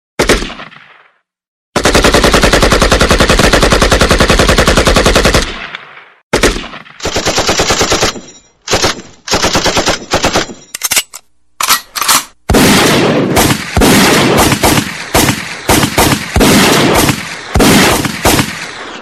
multi-guns_25188.mp3